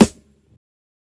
SNARE_FIGHT_U.wav